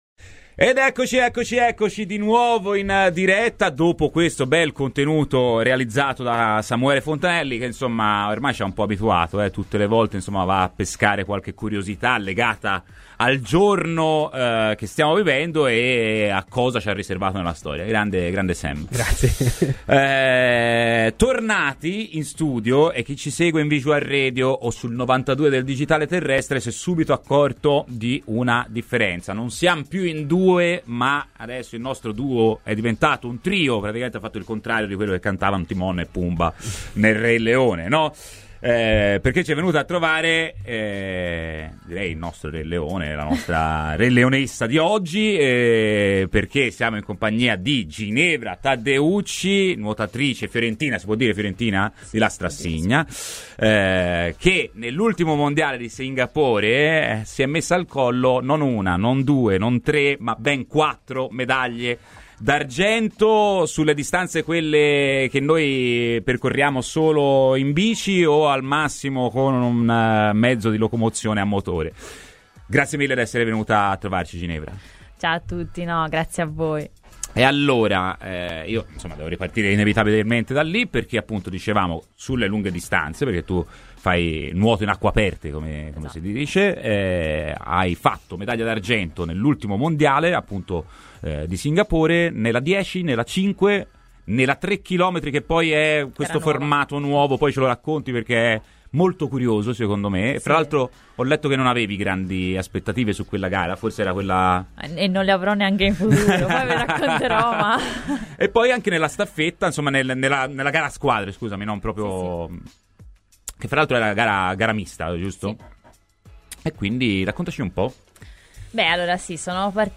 La nuotatrice italiana e tifosa viola, Ginevra Taddeucci , vincitrice della medaglia di bronzo nella 10km nuotata nella Senna alle Olimpiadi di Parigi del 2024 , è stata quest'oggi ospite di Radio FirenzeViola nel corso della trasmissione " Firenze in campo ".